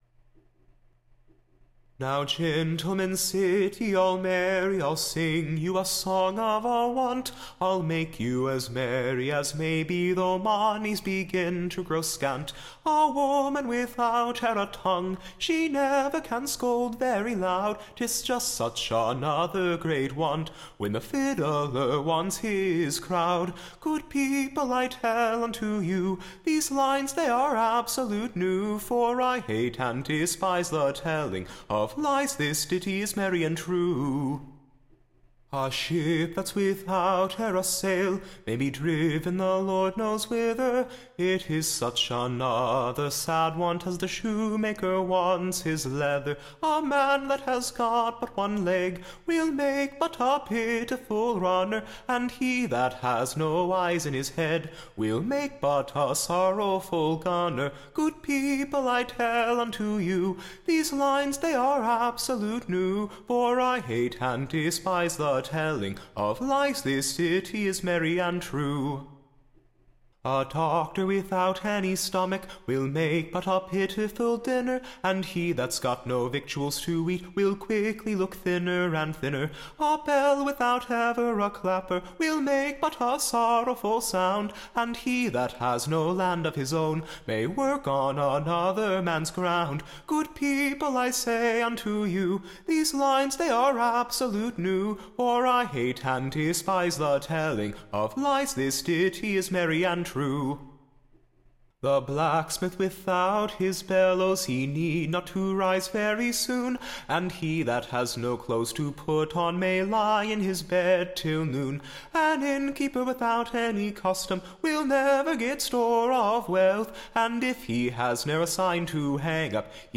Recording Information Ballad Title A true Character of sun- / dry Trades and Call- / ings. / Or, A new Ditty of Innocent / Mirth. Tune Imprint Standard Tune Title Old Simon the King Media Listen 00 : 00 | 3 : 55 Download e.352.m4a (Right click, Save As)